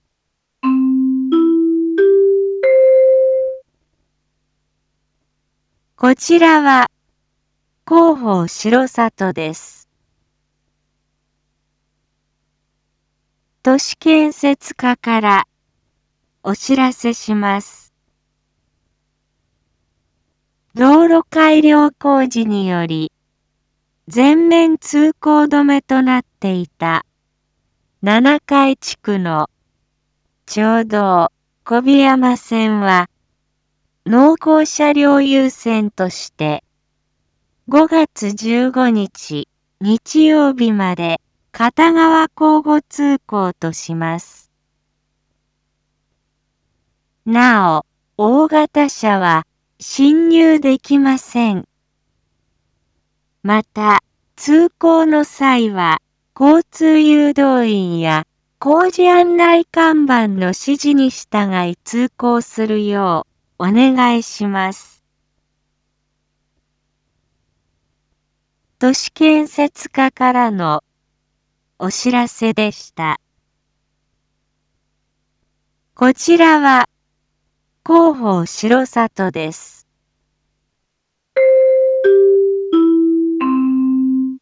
一般放送情報
Back Home 一般放送情報 音声放送 再生 一般放送情報 登録日時：2022-04-27 19:01:25 タイトル：R4.4.27 19時放送分 インフォメーション：こちらは広報しろさとです。